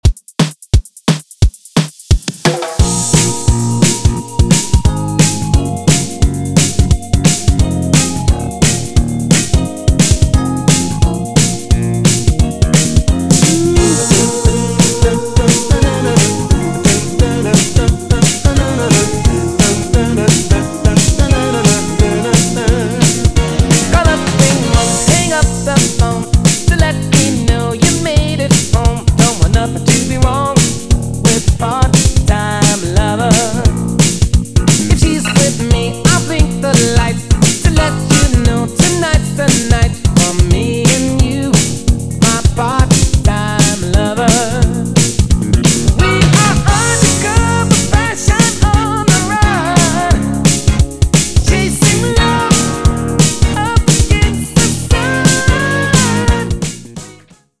guaracha, salsa remix, cumbia remix, EDM latino